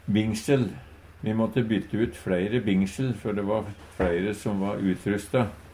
Tilleggsopplysningar Kan òg verte uttala "binsel".